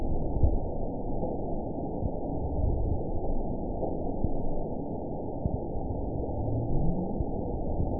event 920347 date 03/17/24 time 22:19:35 GMT (1 year, 3 months ago) score 9.53 location TSS-AB03 detected by nrw target species NRW annotations +NRW Spectrogram: Frequency (kHz) vs. Time (s) audio not available .wav